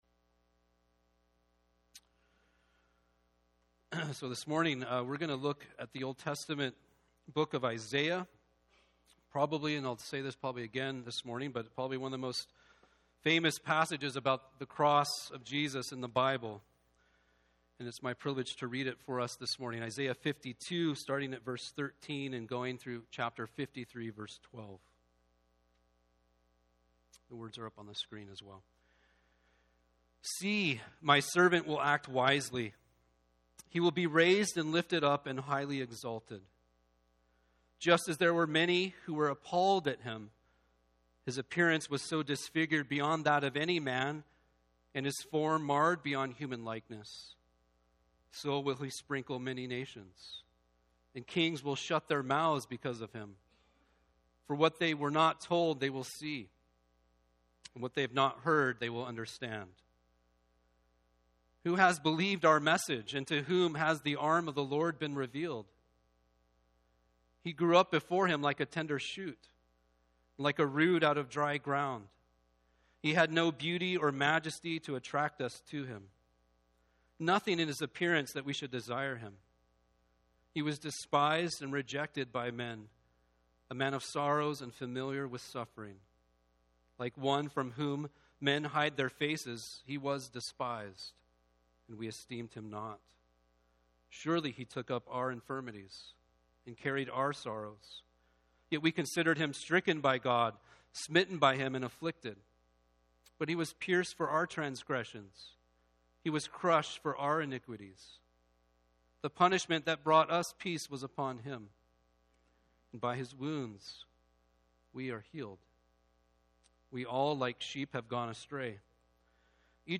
MESSAGES | Maple Ridge Christian Reformed Church